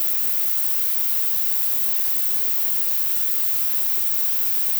Dither
While today’s noize isn’t very musical, It will hopefully be educational.
Typically it lives far below audible levels.